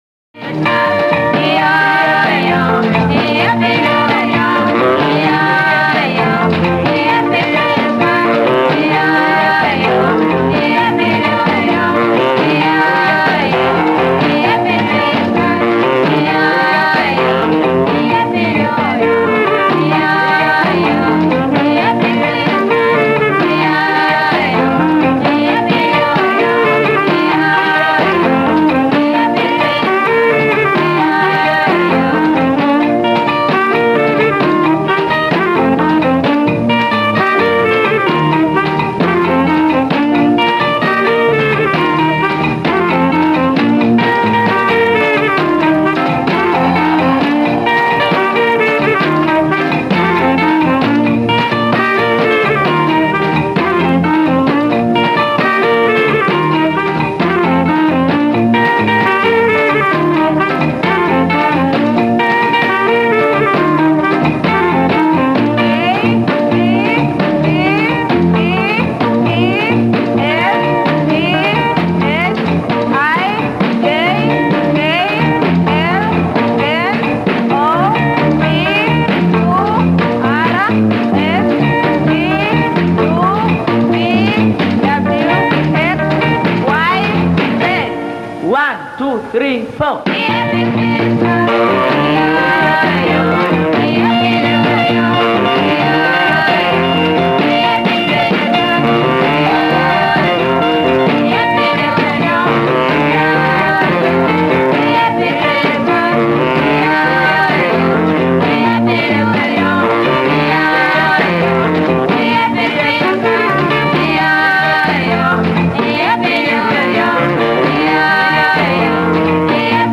Nimeubahatisha umepigwa TBC nikaurekodi fasta